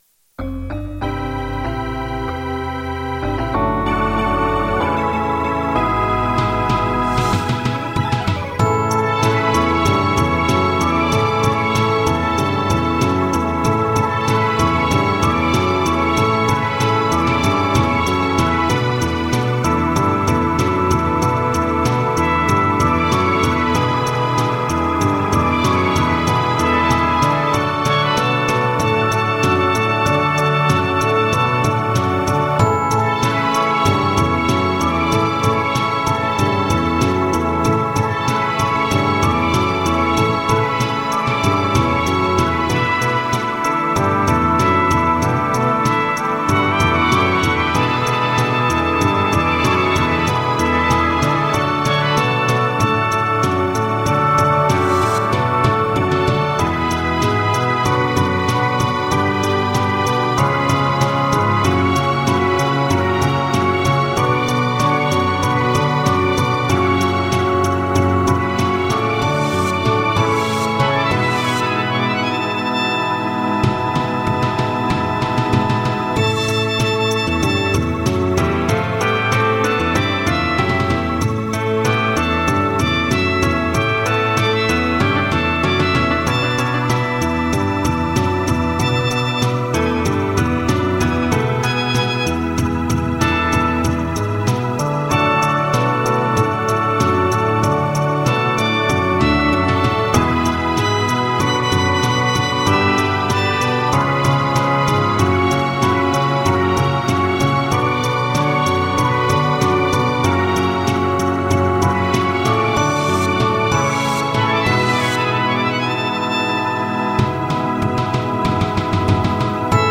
Психоделические мелодии прекрасны, а у тебя еще с изюминкой.